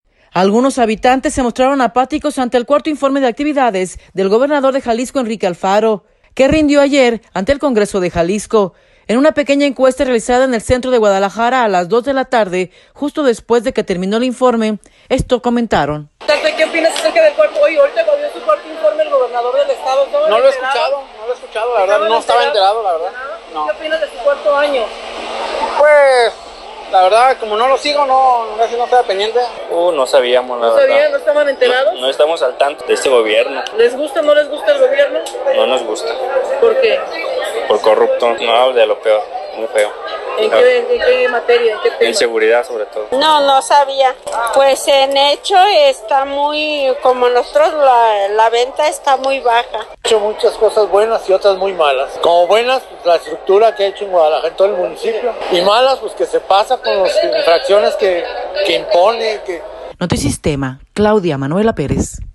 Algunos habitantes se mostraron apáticos ante el Cuarto Informe de Actividades del gobernador de Jalisco, Enrique Alfaro, que rindió ayer ante el Congreso de Jalisco. En una pequeña encuesta realizada en el Centro de Guadalajara a las 2:00 de la tarde, justo después de que terminó el Informe, esto comentaron.